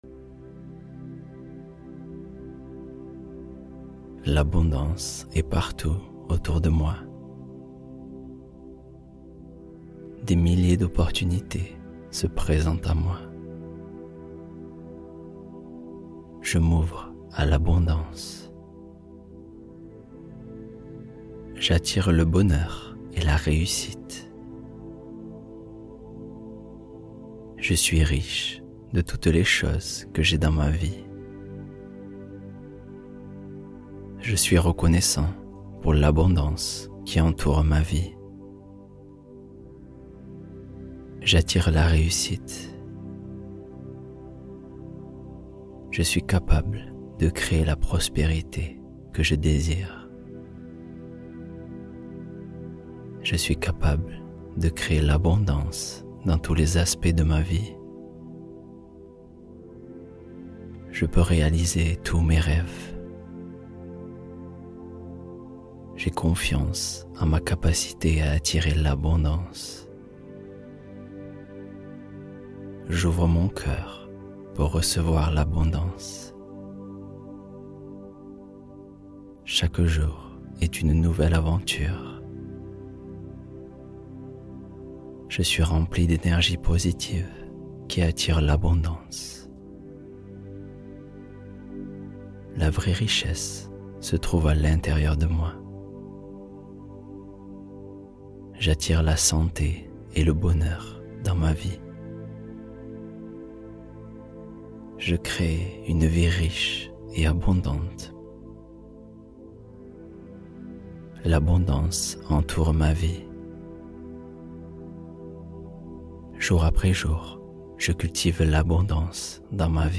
Sommeil sans effort — Histoire du soir apaisante